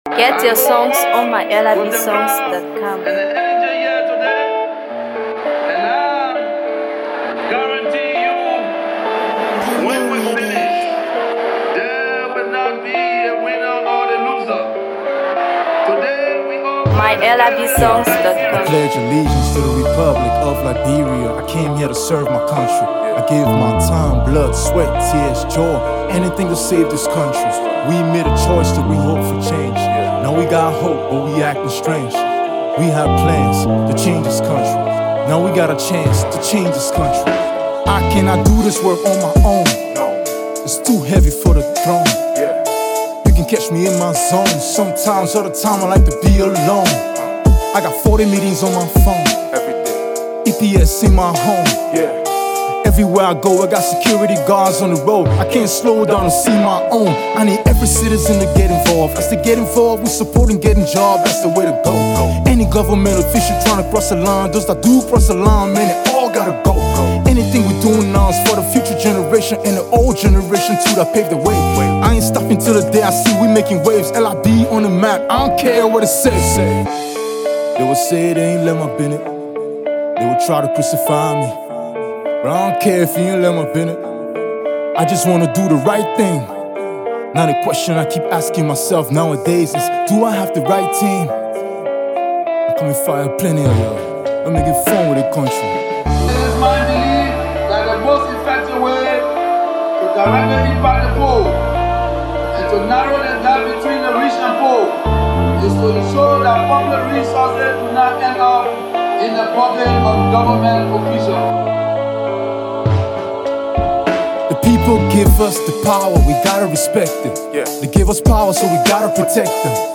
Hip HopMusic